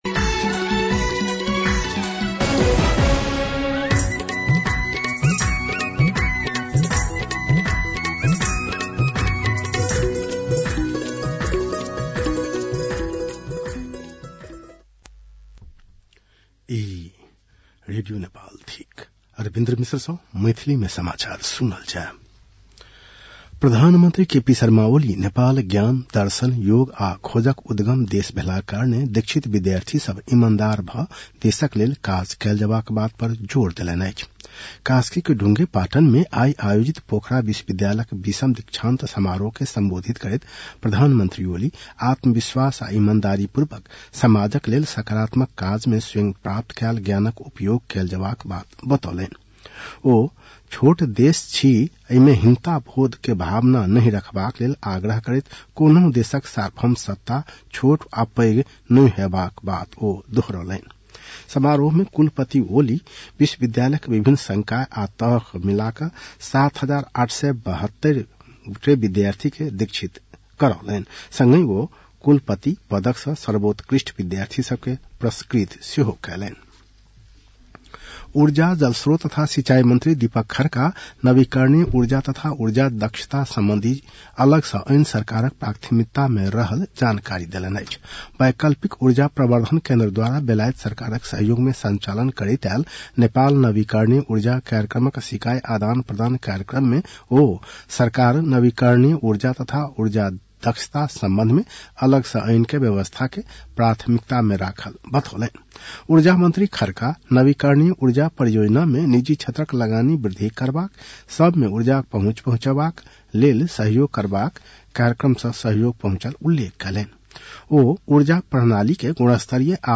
An online outlet of Nepal's national radio broadcaster
मैथिली भाषामा समाचार : ९ फागुन , २०८१